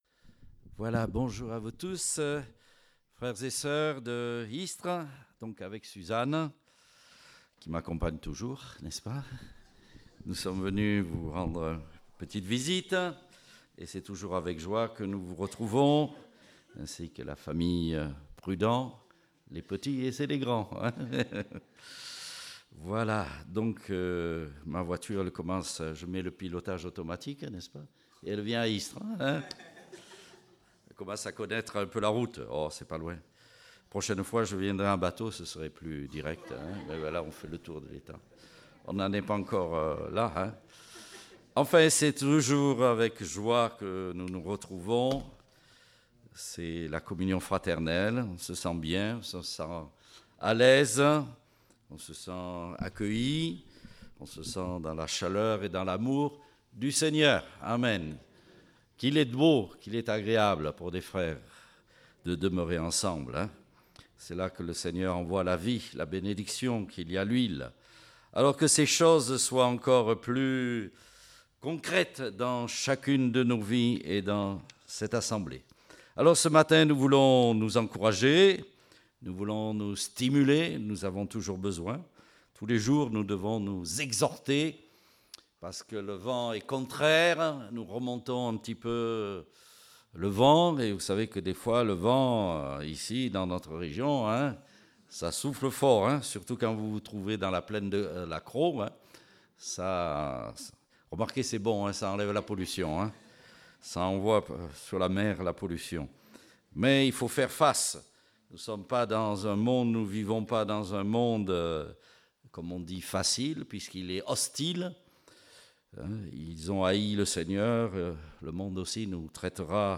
Date : 12 mars 2017 (Culte Dominical)